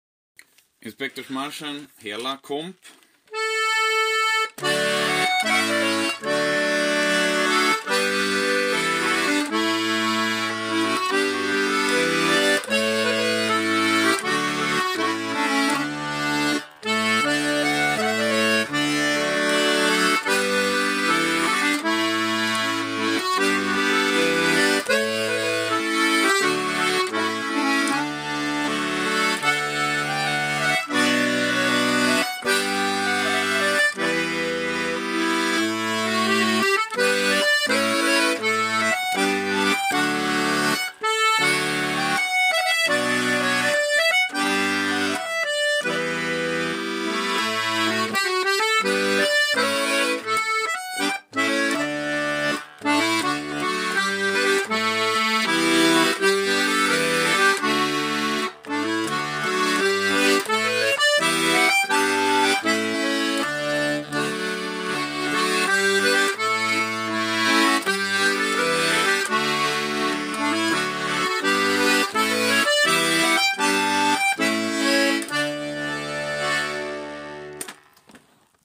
Gånglåt